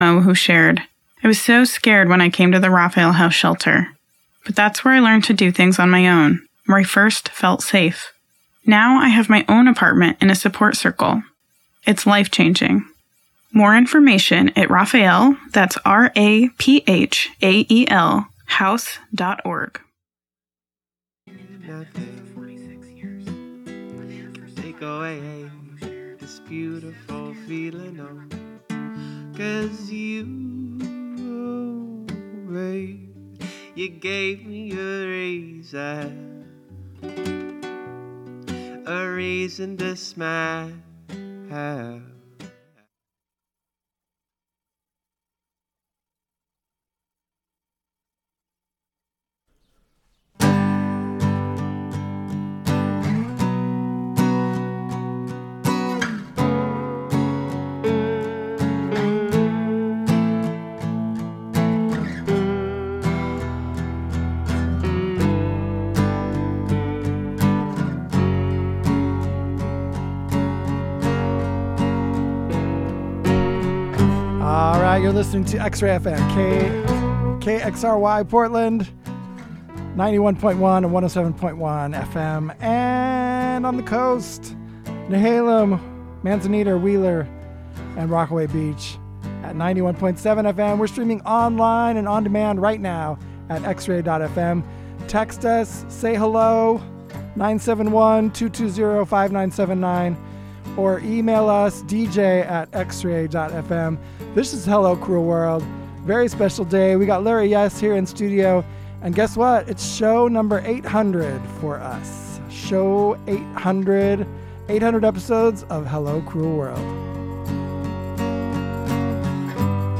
Hello Cruel World brings the worlds of singer/songwriters and acoustic music to you every Thursday from 3-4pm with conversations and in-studio performances as often as possible.